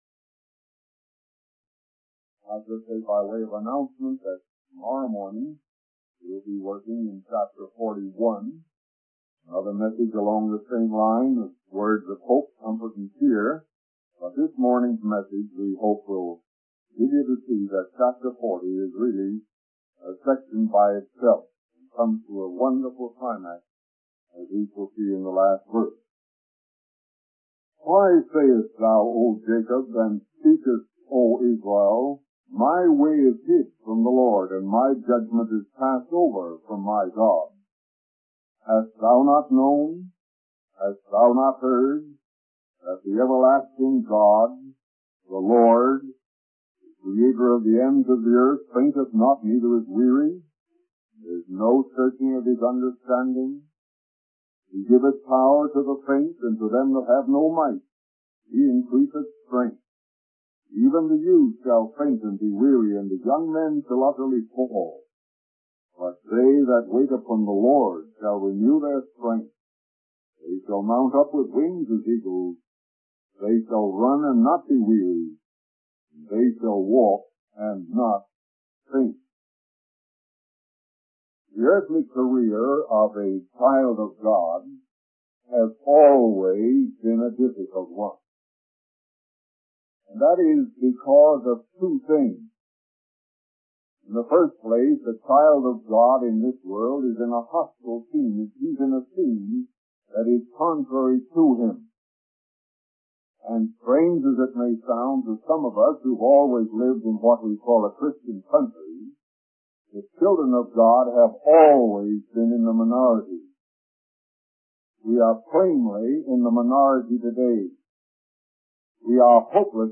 In this sermon, the preacher discusses the questions raised by the enemy concerning God.